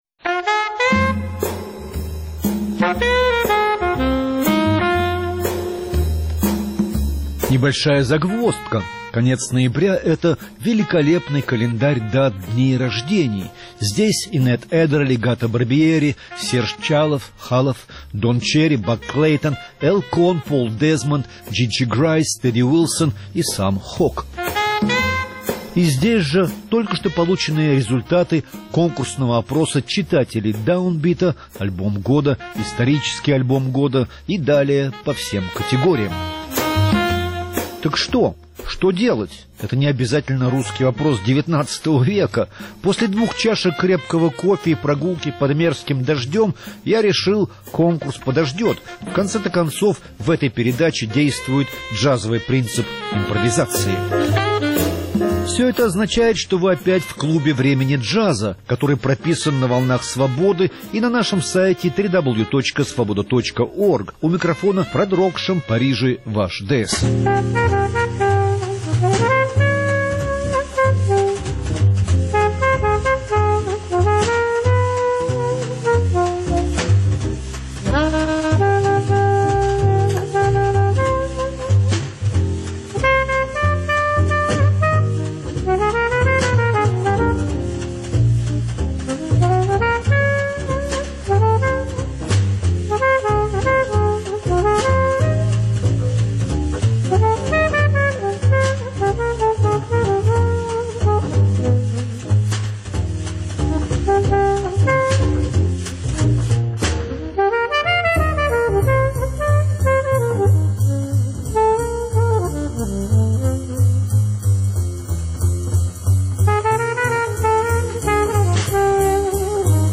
король свингового фортепьяно